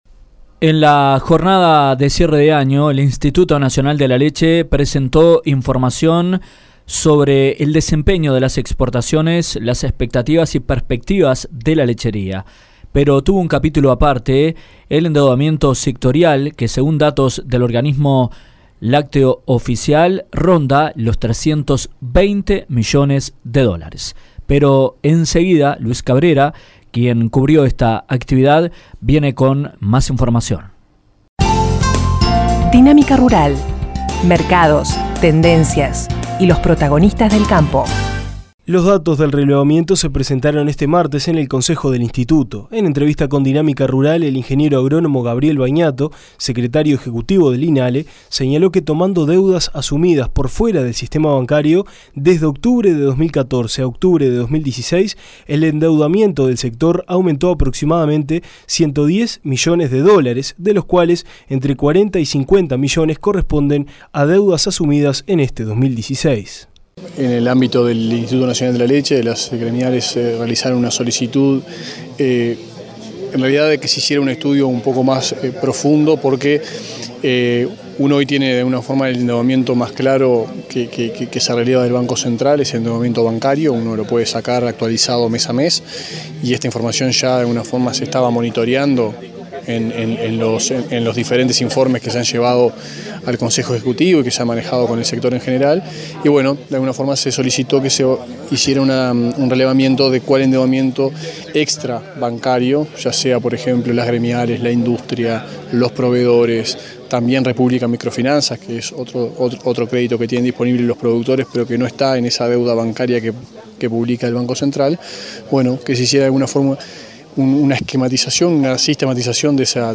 INALE elaboró un informe sobre el endeudamiento extra bancario del sector lácteo que fue presentado en el consejo del instituto. En entrevista con Dinámica Rural el Ing.